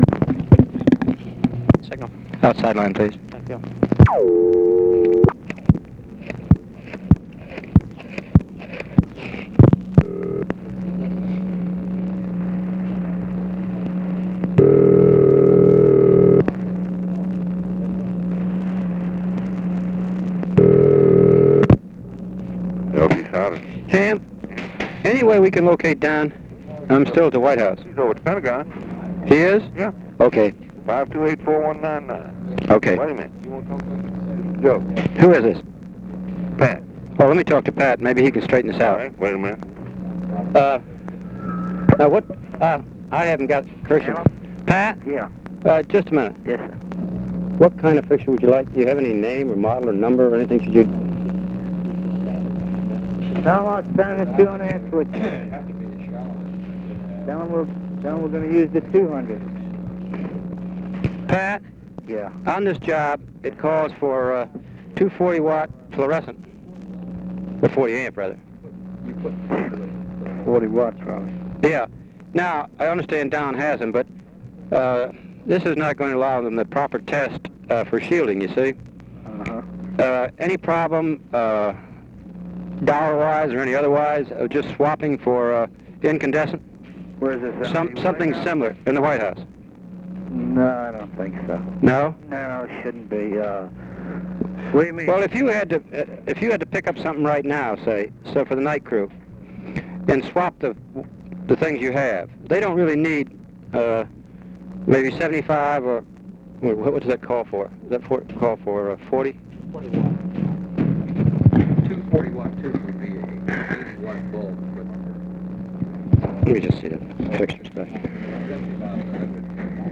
Conversation with UNIDENTIFIED MALE, UNIDENTIFIED MALE and UNIDENTIFIED MALE
Secret White House Tapes